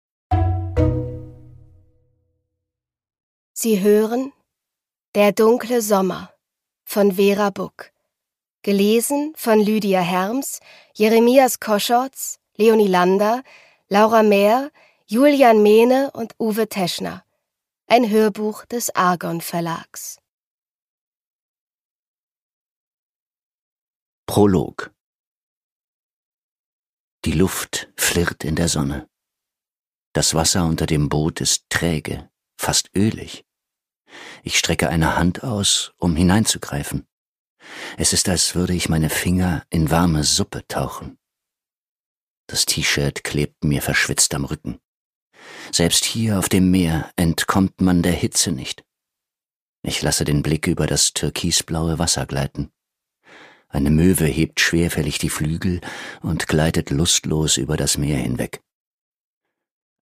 Vera Buck: Der dunkle Sommer (Ungekürzte Lesung)
Produkttyp: Hörbuch-Download